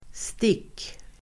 Uttal: [stik:]